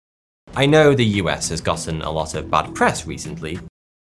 But examples aren’t hard to find, from young and not so young speakers:
But as we heard in the clips above, it’s possible to find Brits using gotten with a wide variety of meanings.